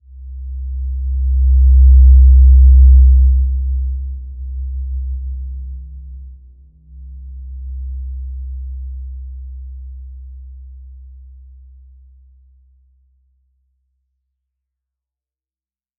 Simple-Glow-C2-mf.wav